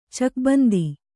♪ cakbandi